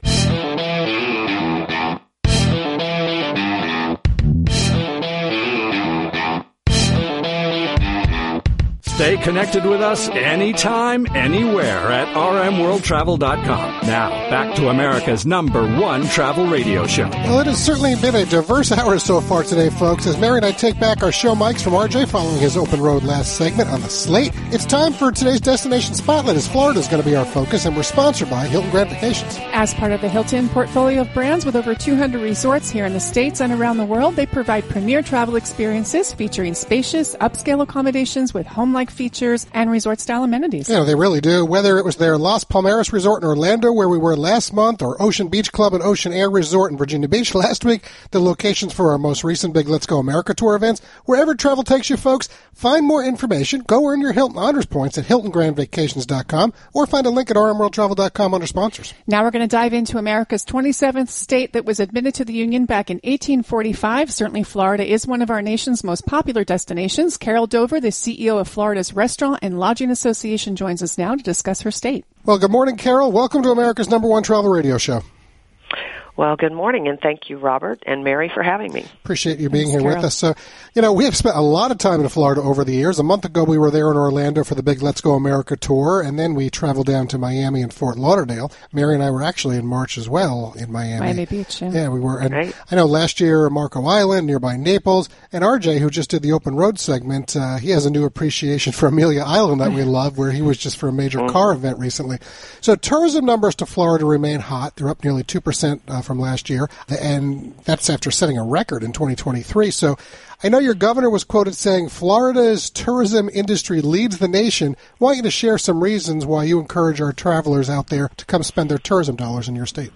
And during the live national broadcast of America’s #1 Travel Radio Show on June 14th 2025 — the “Sunshine State” had our attention.